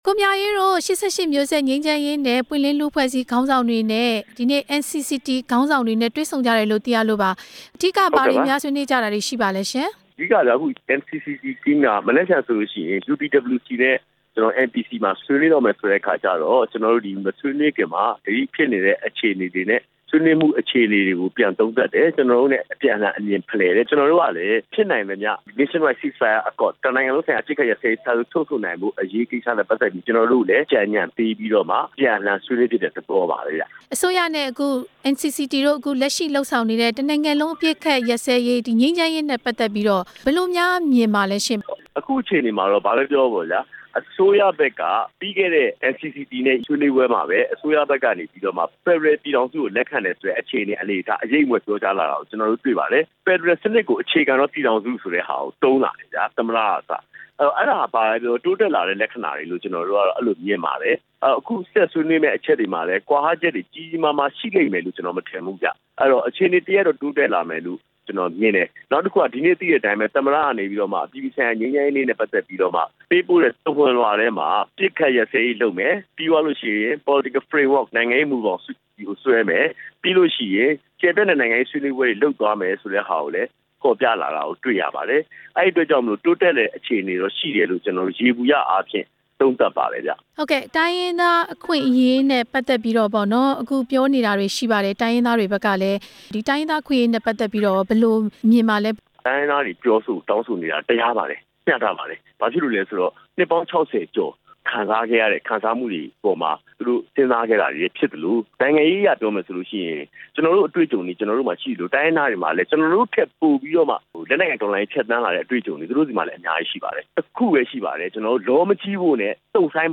မြန်မာနိုင်ငံရဲ့ ငြိမ်းချမ်းရေး ဖြစ်စဉ်တွေ အကြောင်း မေးမြန်းချက်